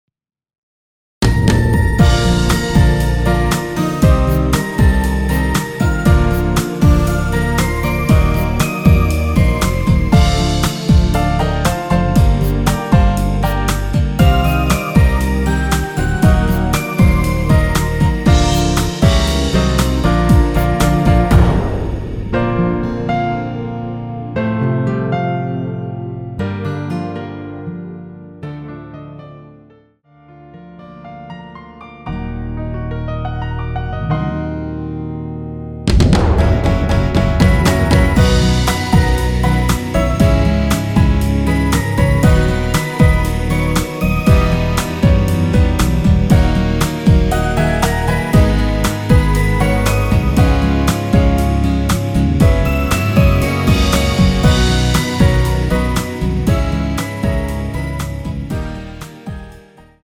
원키에서(-5)내린 MR입니다.
Bb
앞부분30초, 뒷부분30초씩 편집해서 올려 드리고 있습니다.
중간에 음이 끈어지고 다시 나오는 이유는